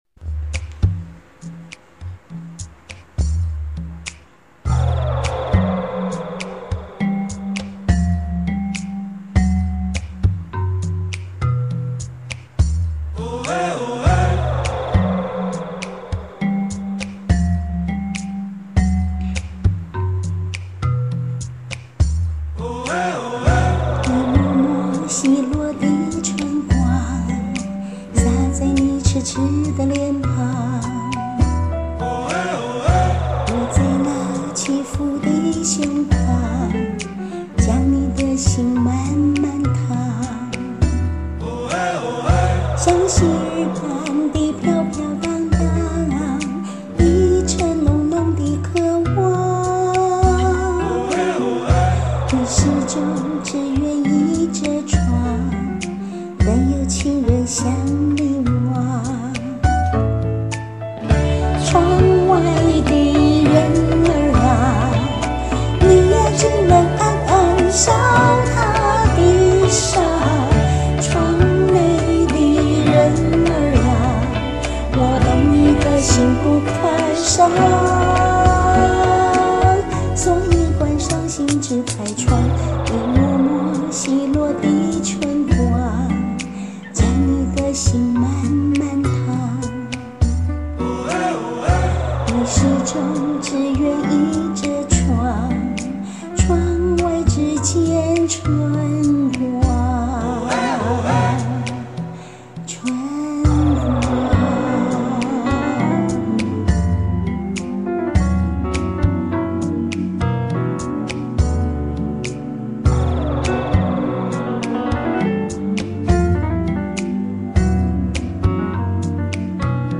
帶點神祕東方調的旋律
這首的旋律真的很特別~唱的好不簡單哦~~讚!!
變奏部份還完全沒有換氣點............